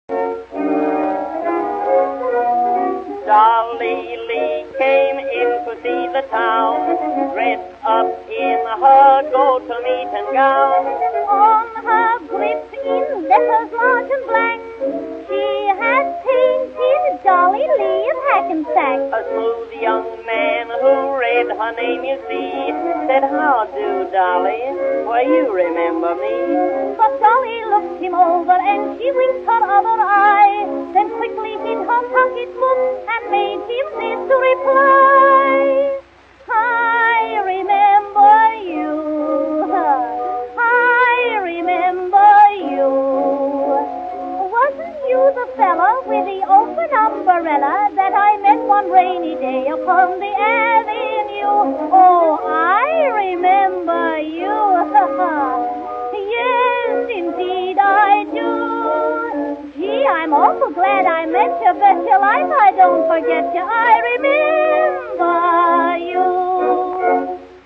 Vocalist's Showcase Presenting Comic Songs By :
(RECORDED 1909-1911)
THIS CUSTOM CD WAS MADE FROM RARE ORIGINAL DISC RECORDS.
ADVANCED FILTERING SYSTEM WAS USED FOR VERY CLEAN SOUND.